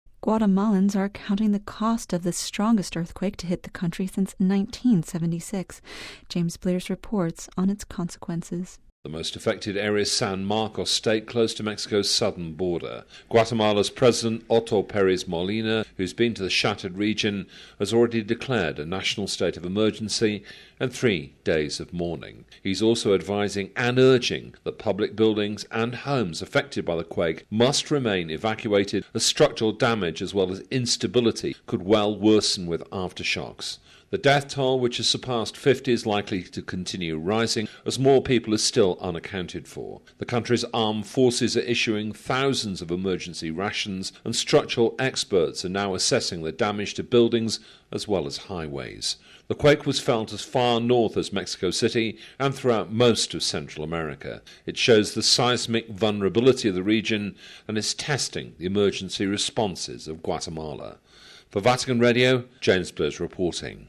Listen to the full report by regional correspondent